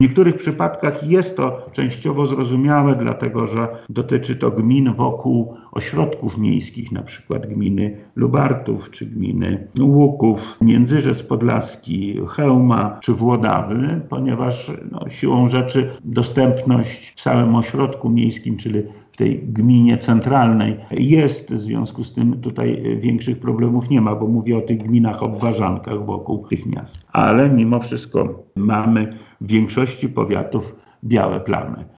– Tylko w trzech powiatach – świdnickim, puławskim i janowskim – punkty szczepień będą w każdej gminie – mówi wojewoda lubelski Lech Sprawka: